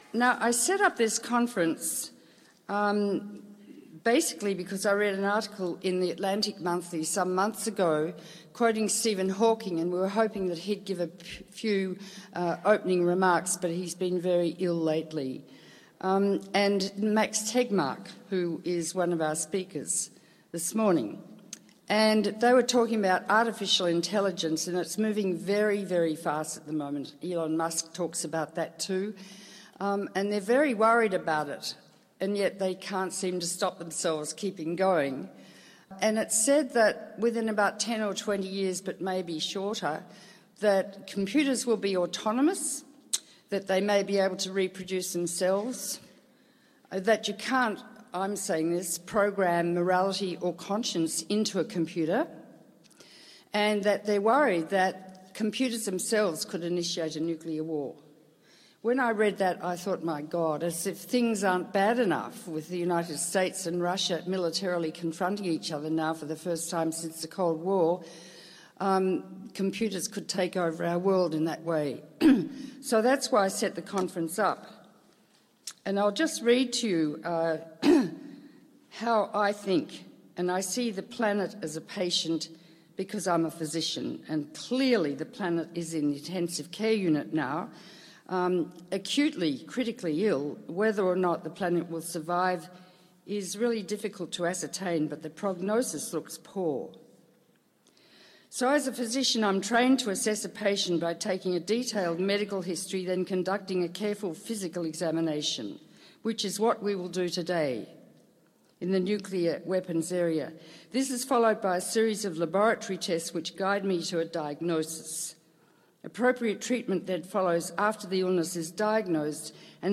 Helen Caldicott: Opening Remarks | Dynamics of Possible Nuclear Extinction Symposium, 02-28-15
This presentation of Dr. Helen Caldicott was recorded on 28 February 2015 at The Dynamics of Possible Nuclear Extinction Symposium , presented by The Helen Caldicott Fondation , at The New York Academy of Medicine .